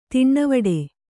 ♪ tiṇṇavaḍe